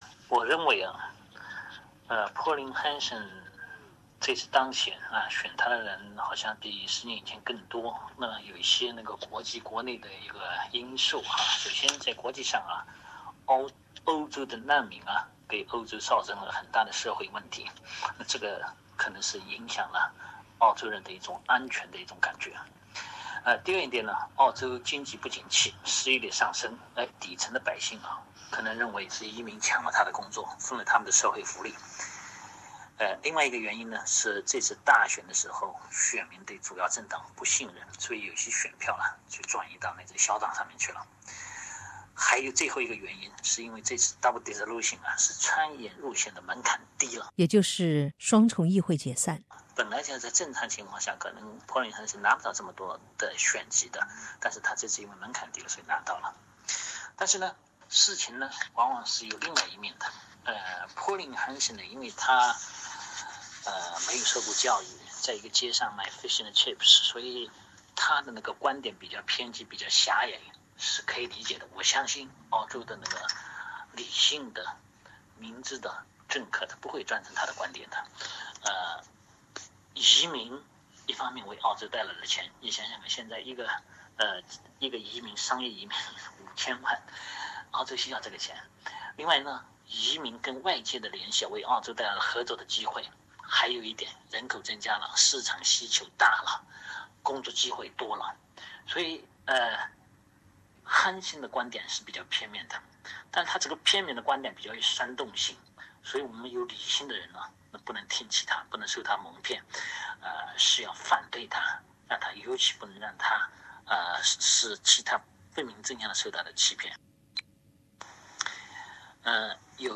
请听采访录音 评论仅为个人观点，不代表本台立场